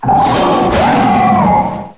cry_not_thundurus_therian.aif